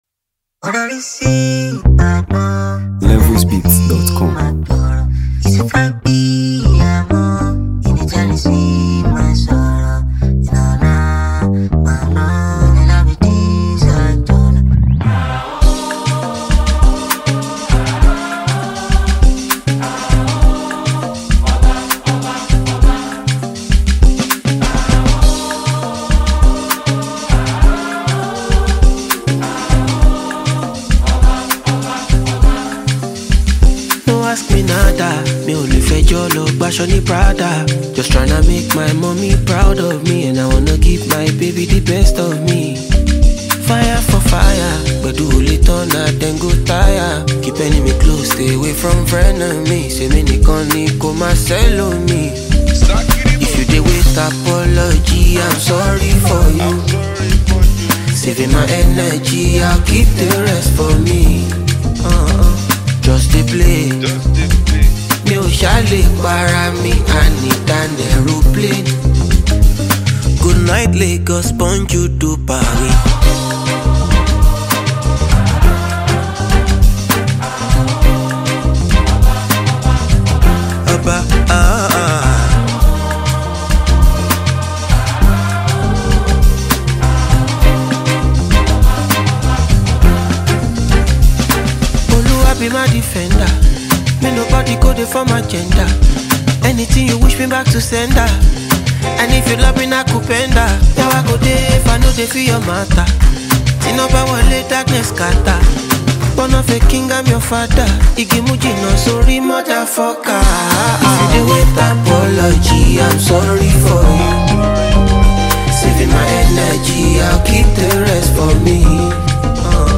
Afrobeats
a majestic and soulful track
smooth vocals